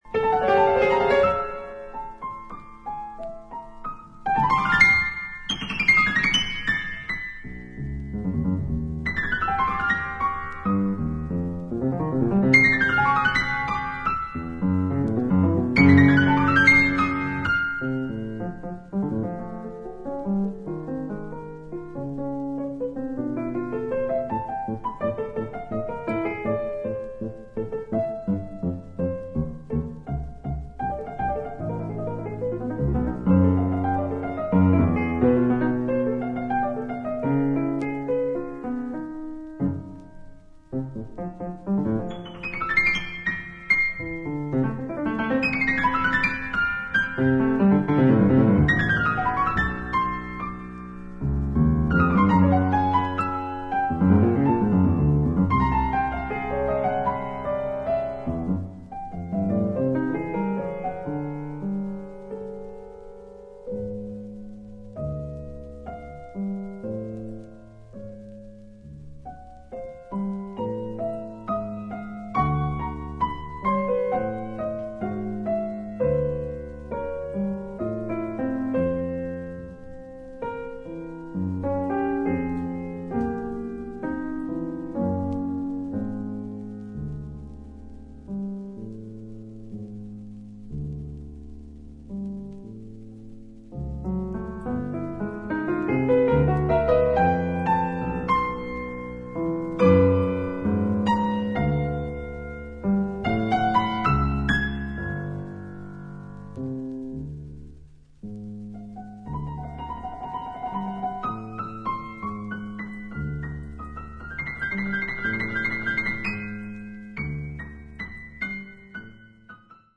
内省的でありながら、フェロー独特の穏やかな響きが感じられるピアノ独奏曲が計4曲収録。